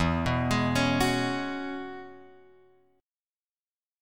E6add9 chord {0 2 x 1 2 2} chord